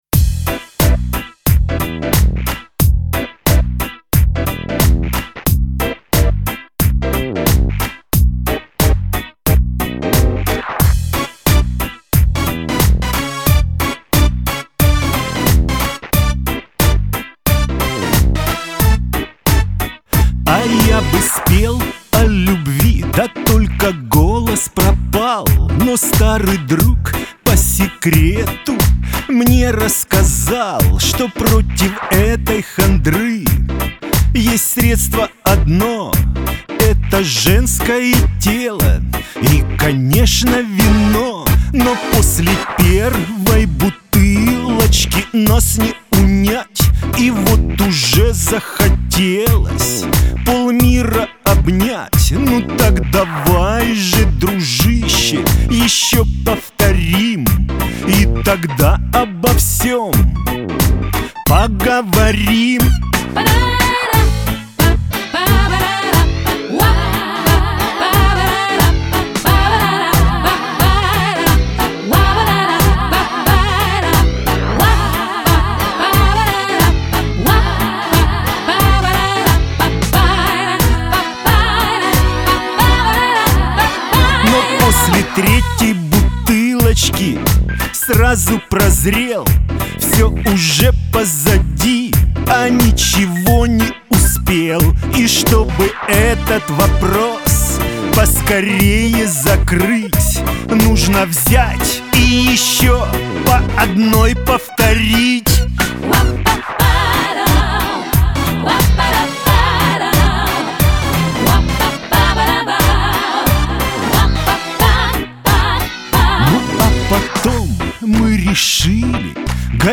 бэк-вокал